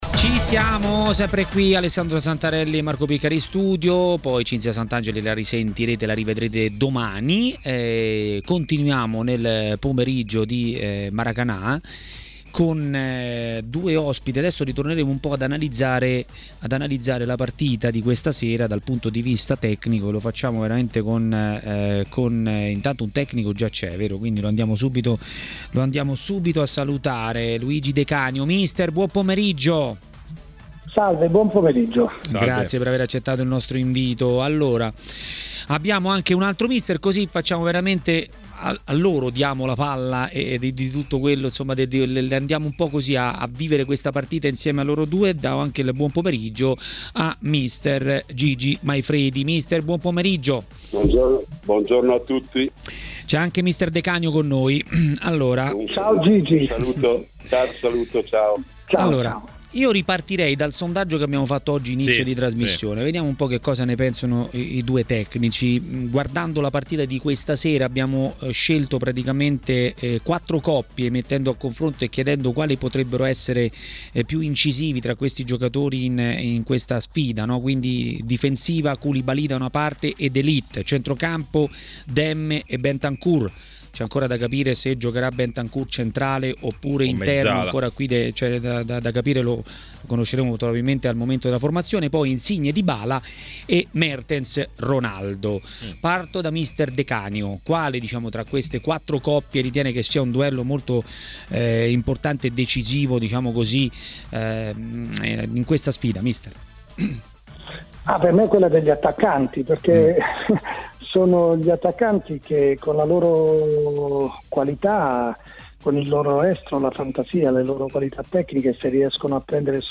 L'ex tecnico Gigi Maifredi ha parlato della finale di Coppa Italia e non solo a Maracanà, nel pomeriggio di TMW Radio.